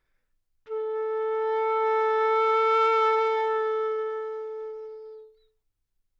长笛单音（吹得不好） " 长笛 A4 badtimbre
描述：在巴塞罗那Universitat Pompeu Fabra音乐技术集团的goodsounds.org项目的背景下录制。
Tag: 好声音 单注 多样本 A4 纽曼-U87 长笛